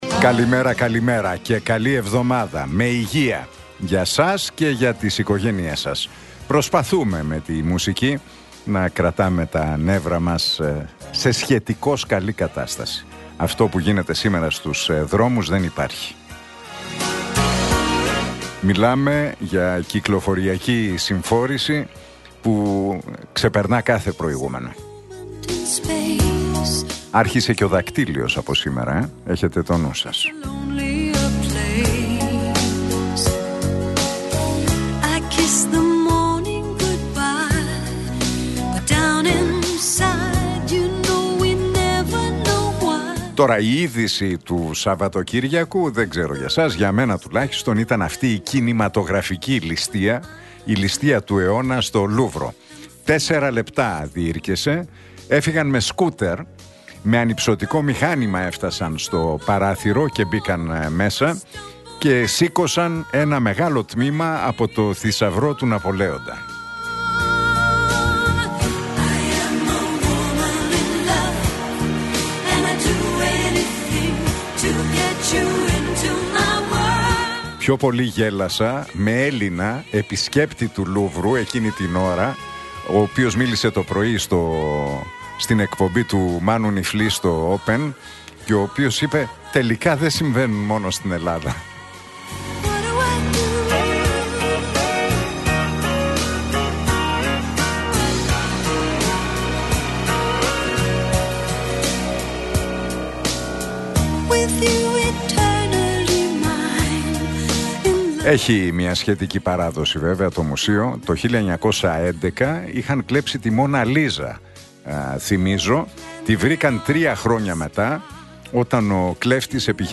Ακούστε το σχόλιο του Νίκου Χατζηνικολάου στον ραδιοφωνικό σταθμό Realfm 97,8, τη Δευτέρα 20 Οκτώβριου 2025.